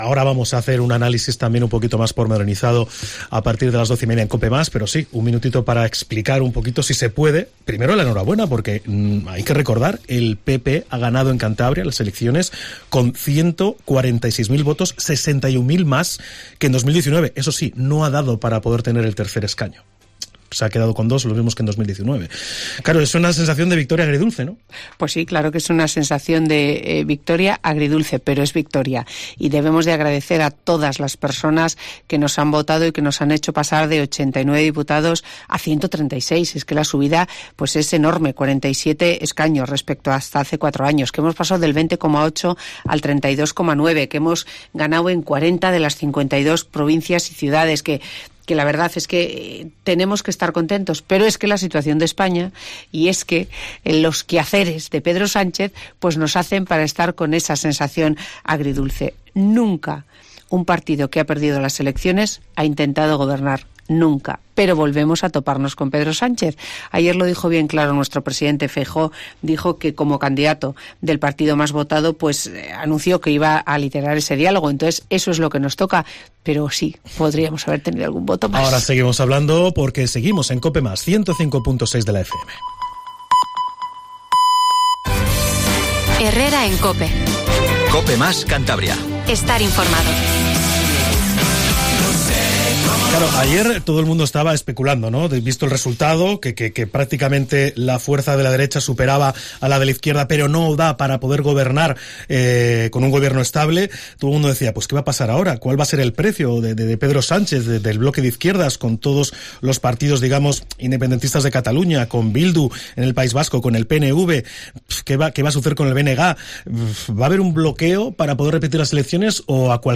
Escucha a Gema Igual, alcaldesa de Santander, analizar el resultado del 23J y los actos de la Semana Grande